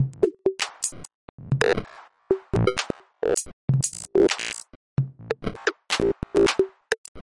Descarga de Sonidos mp3 Gratis: bateria 4.
golpearon_21.mp3